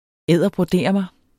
Udtale [ ˈeðˀʌbʁoˈdeˀʌmə ] eller [ ˈεðˀʌ- ]